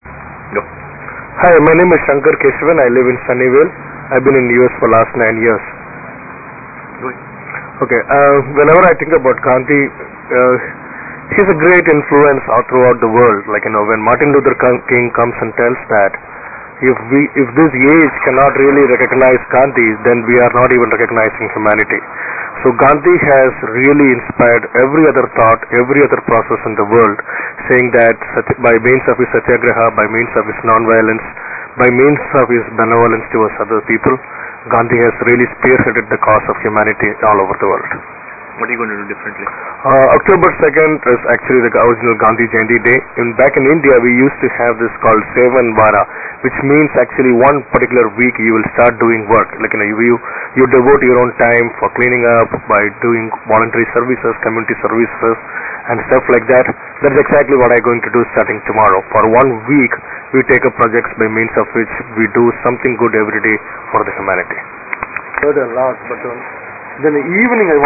MP3 Interviews
Interview 2-